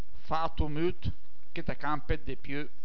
Se hai un browser che supporta i file .wav, cliccando sui proverbi scritti in bergamasco potrai ascoltarne anche la pronuncia, almeno per i primi 80 .